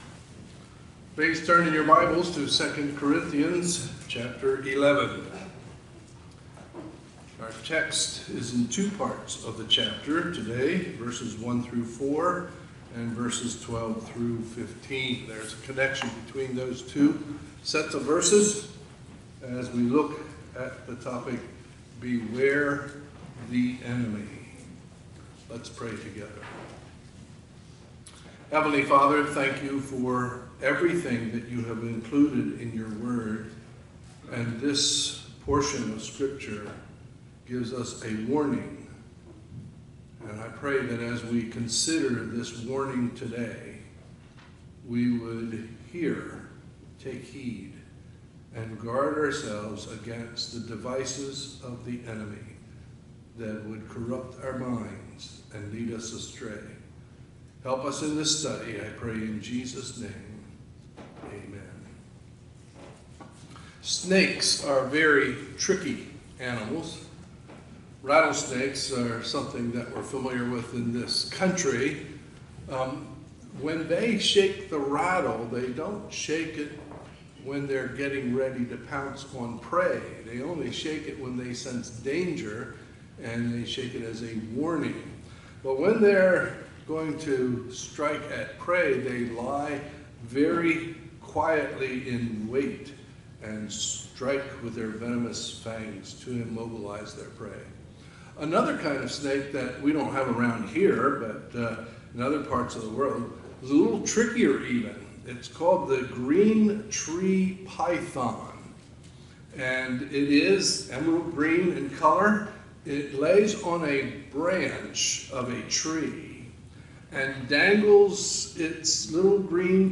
Sunday, October 11, 2020 – Sunday Morning Service